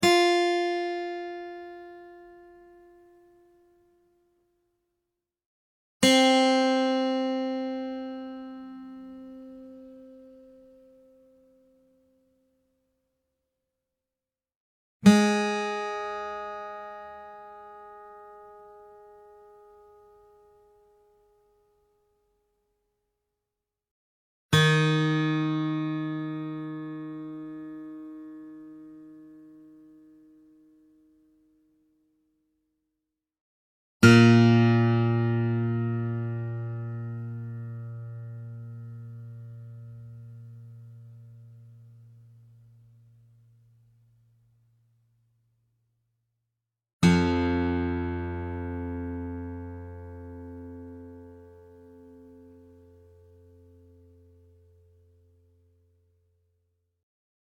Real acoustic guitar sounds in Half Step Up Tuning
Guitar Tuning Sounds